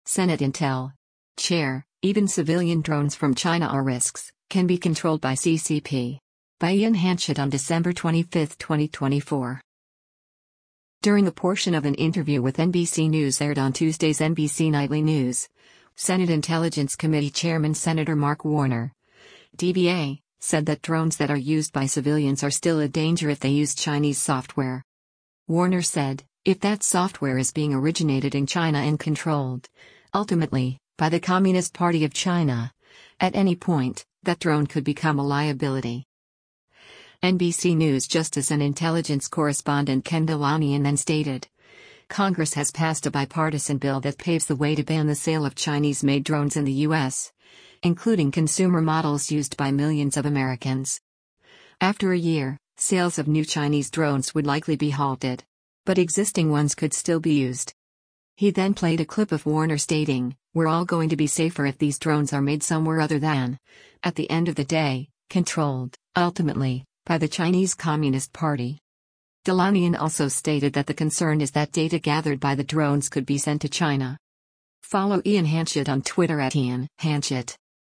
During a portion of an interview with NBC News aired on Tuesday’s “NBC Nightly News,” Senate Intelligence Committee Chairman Sen. Mark Warner (D-VA) said that drones that are used by civilians are still a danger if they use Chinese software.